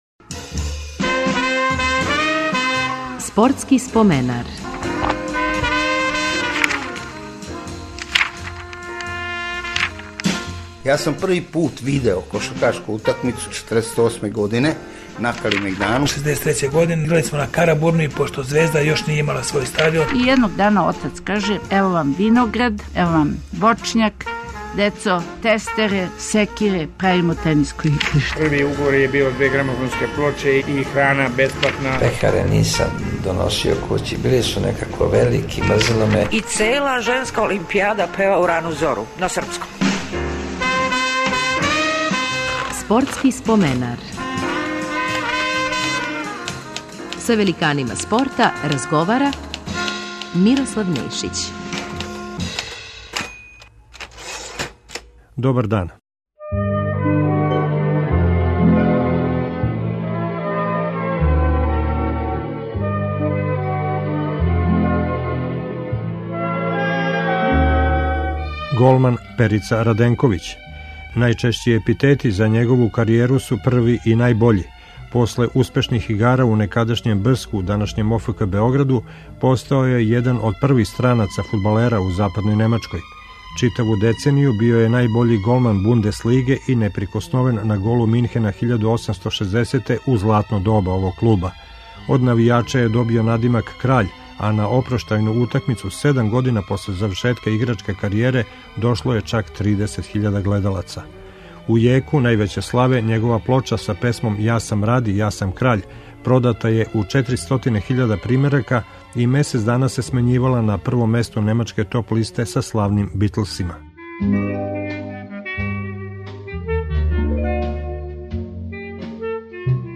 Гост емисије ће бити фудбалер Петар Раденковић.